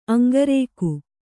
♪ aŋgarēku